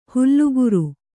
♪ hulluguru